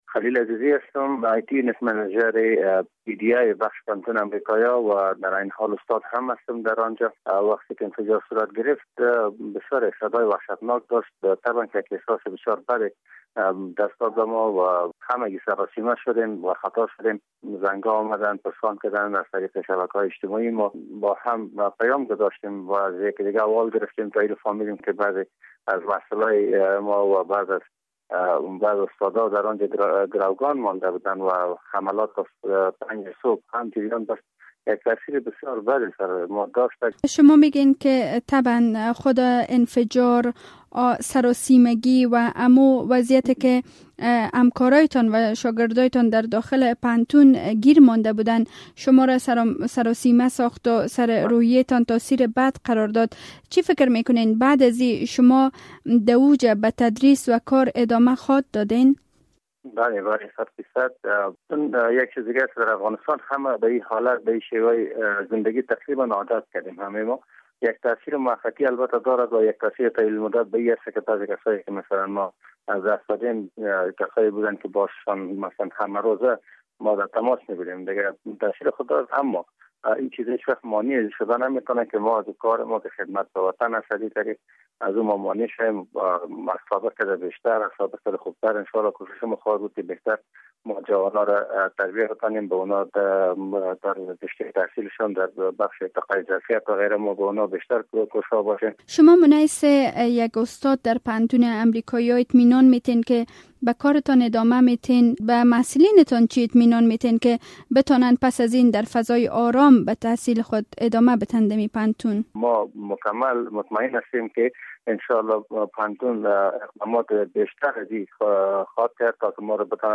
گفتگو با یکی از استادان پوهنتون امریکایی در مورد حملۀ دیشب
مصاحبه - صدا